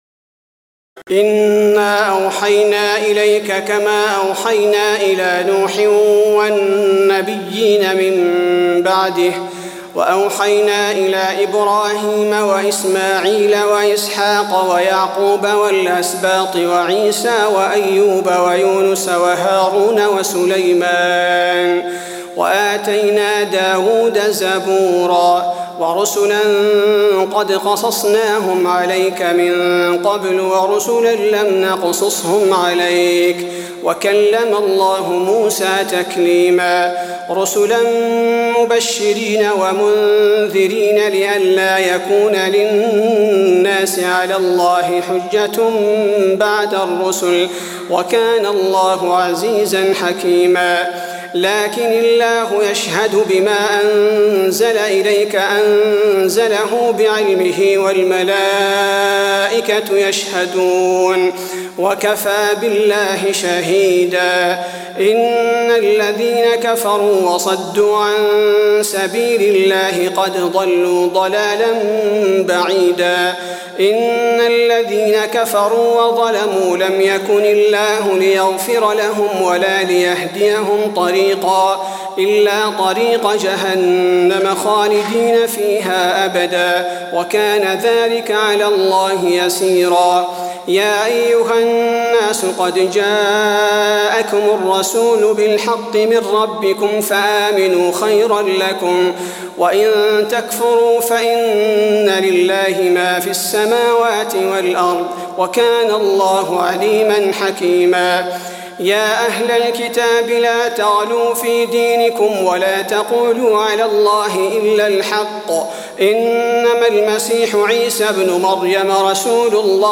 تراويح الليلة السادسة رمضان 1423هـ من سورتي النساء (163-176) و المائدة (1-32) Taraweeh 6 st night Ramadan 1423H from Surah An-Nisaa and AlMa'idah > تراويح الحرم النبوي عام 1423 🕌 > التراويح - تلاوات الحرمين